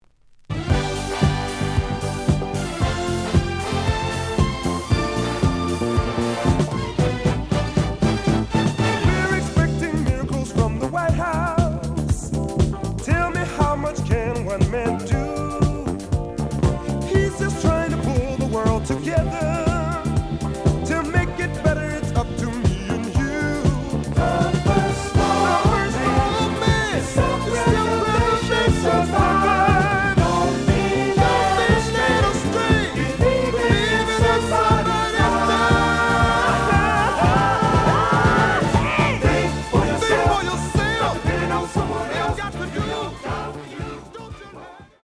The audio sample is recorded from the actual item.
●Format: 7 inch
●Genre: Disco
Slight edge warp. But doesn't affect playing.